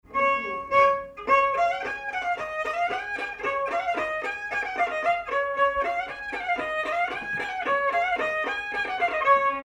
danse : mazurka
circonstance : bal, dancerie
Pièce musicale inédite